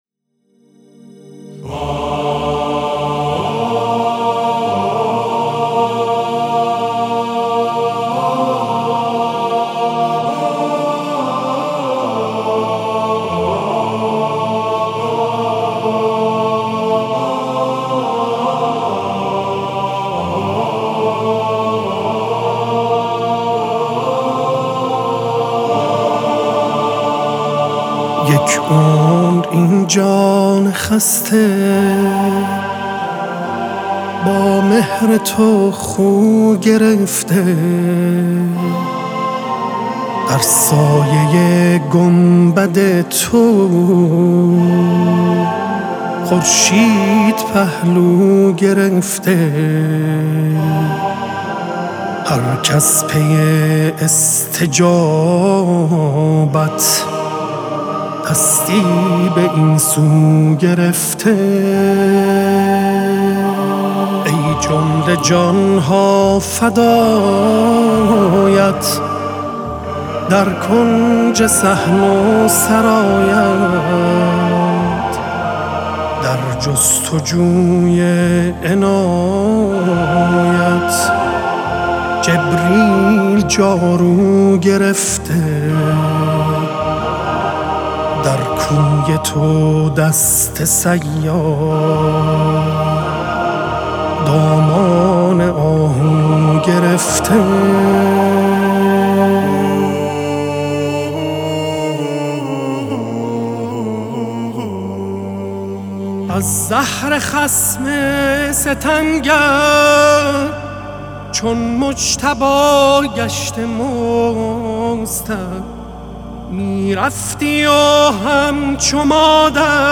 تنظيم و همخوانی